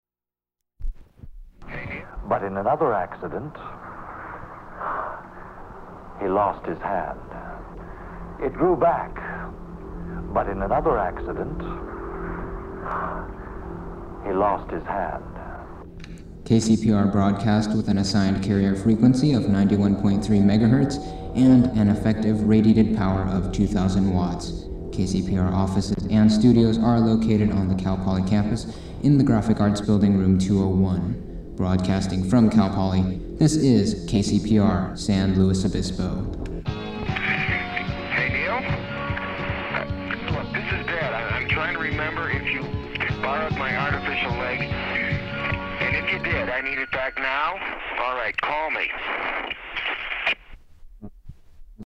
Artificial Leg [station] ID
Form of original Audiocassette